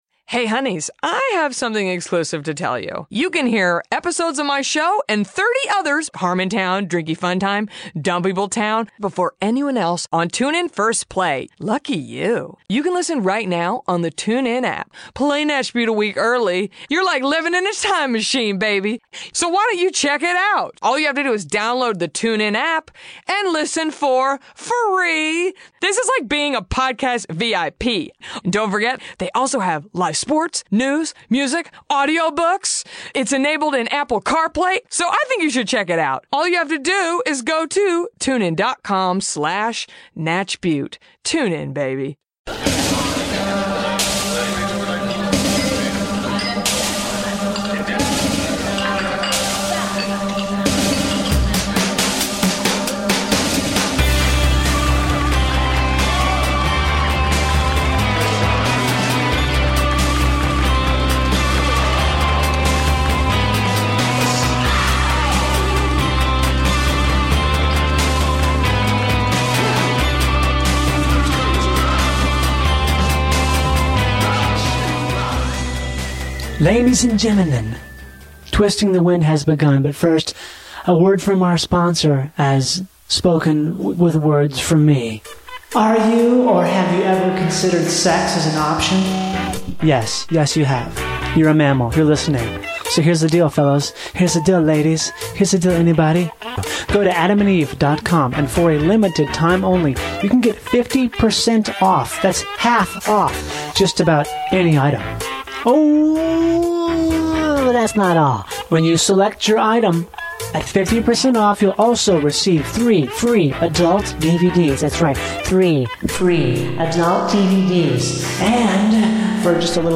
Music is played on a portable record player, Pinon and Juniper wood is toasted.